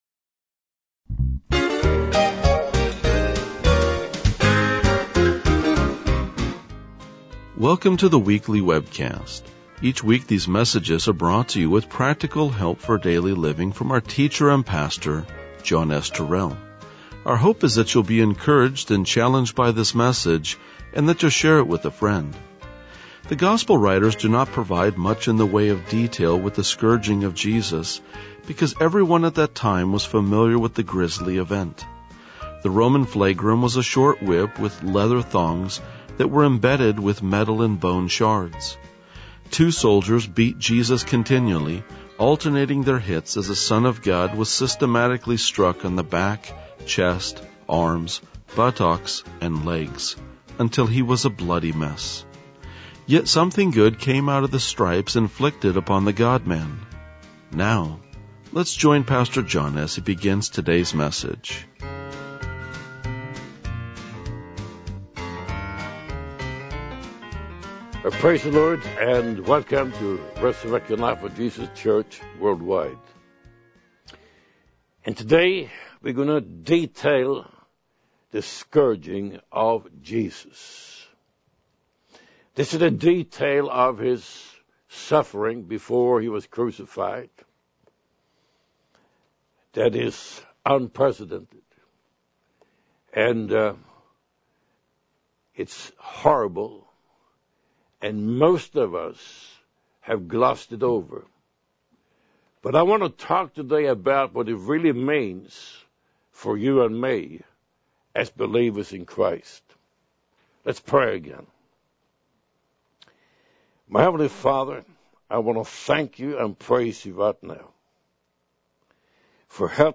RLJ-1981-Sermon.mp3